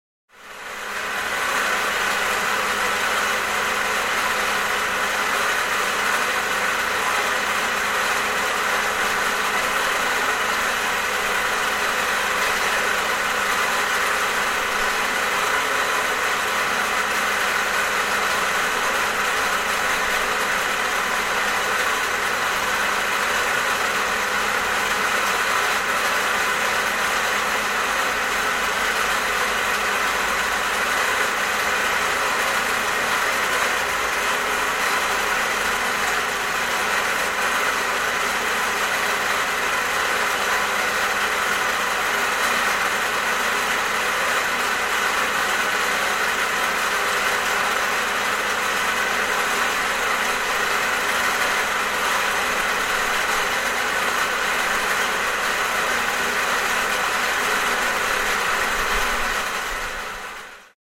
На этой странице собраны звуки работы водяных насосов разных типов.
Шум насоса перекачивающего воду